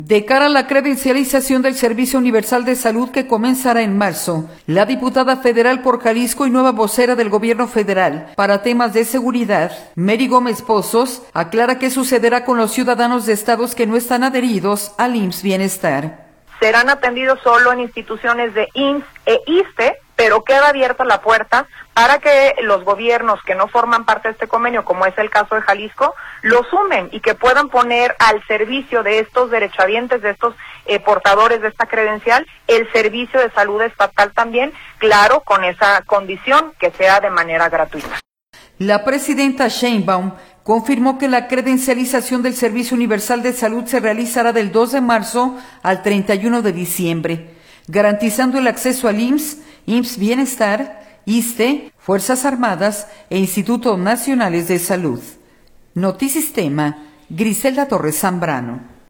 De cara a la credencialización del servicio universal de salud que comenzará en marzo, la diputada federal por Jalisco y nueva vocera del Gobierno Federal para temas de seguridad, Mery Gómez Pozos, aclara qué sucederá con los ciudadanos de estados que no están adheridos al IMSS-Bienestar.